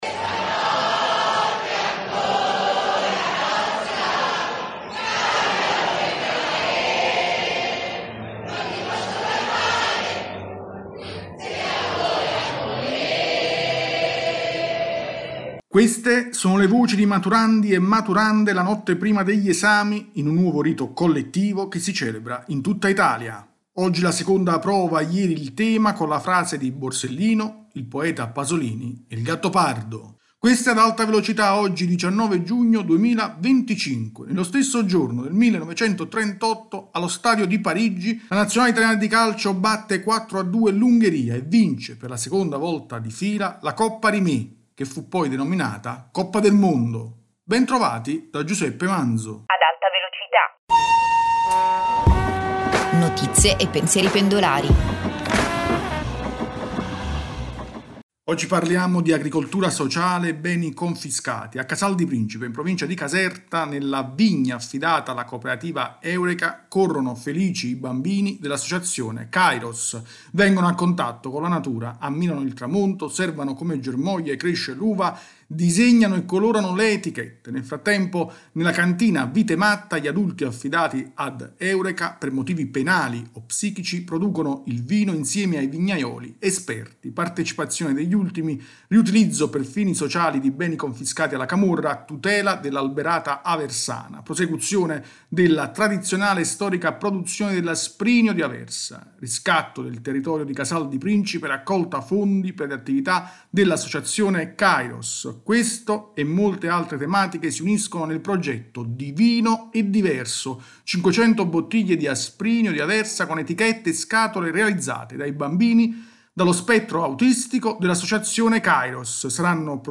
[Intro: Queste le voci di maturandi e maturande la notte prima degli esami in un nuovo rito collettivo che si celebra in tutta Italia: oggi la seconda prova, ieri il tema con la frase di Borsellino, il poeta Pasolini e Il Gattopardo.